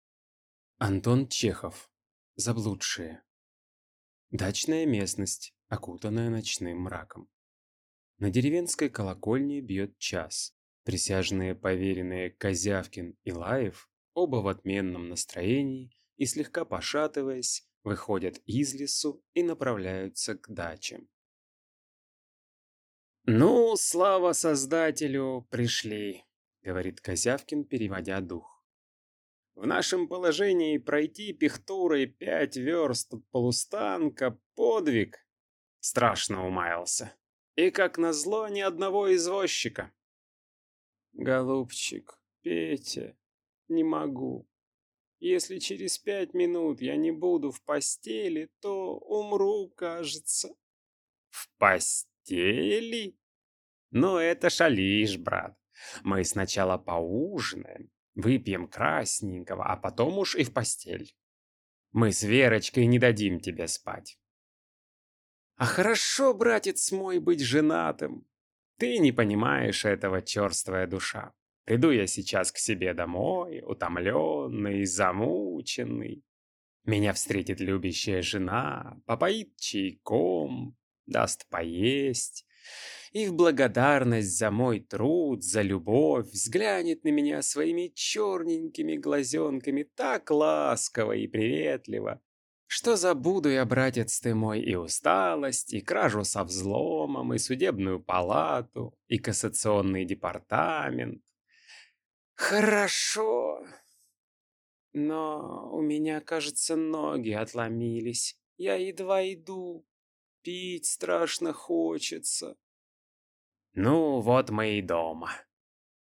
Аудиокнига Заблудшие | Библиотека аудиокниг
Прослушать и бесплатно скачать фрагмент аудиокниги